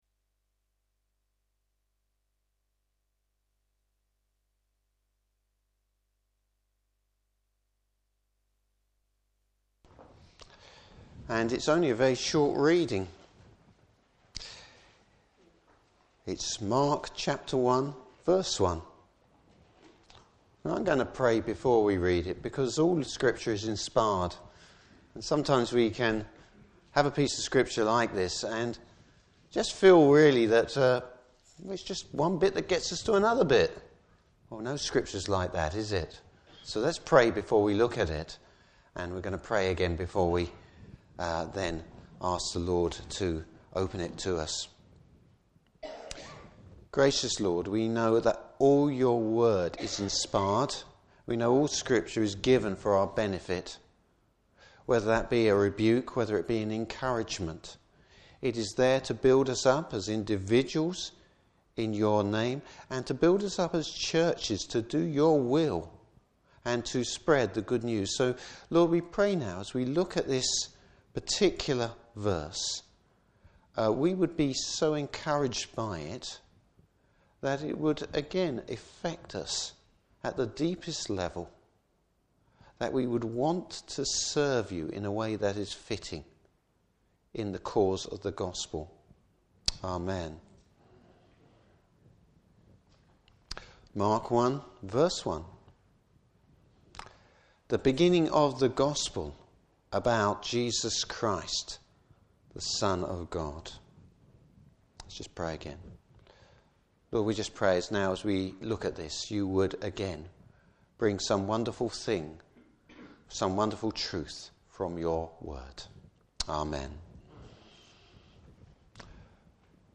Passage: Mark 1:1 Service Type: Christmas Day Evening Service.